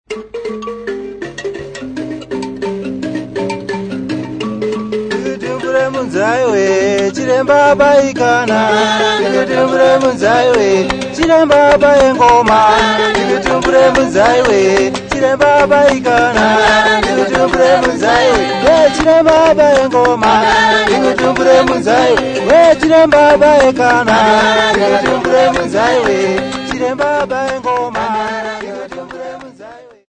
3 Karanga women
Folk music--Africa
Field recordings
Africa Zimbabwe Gweru f-rh
Dance song for Shangara dance for men and women with Njari Mbira and clapping accompaniment.